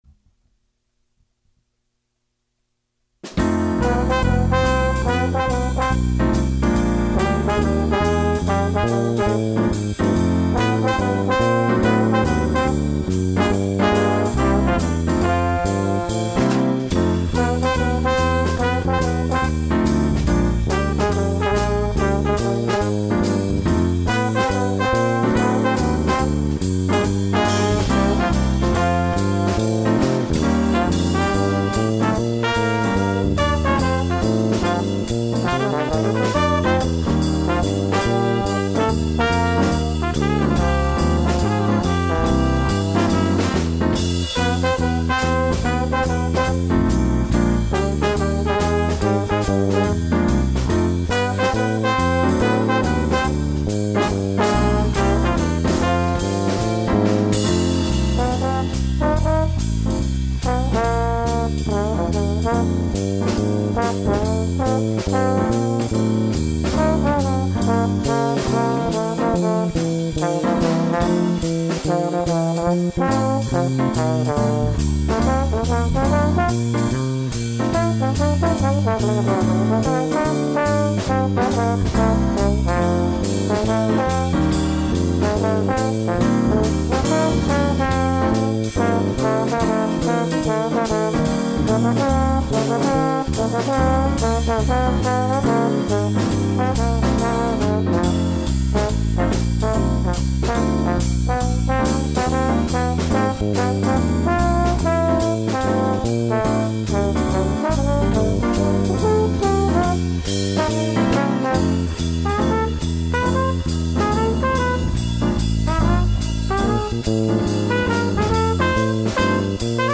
Jazz, John Coltrane, Music, Thelonious Monk Leave a comment
Also, I have recorded all of the instruments and mixed the audio myself. The instruments recorded are trumpet, trombone, piano, bass, and drums.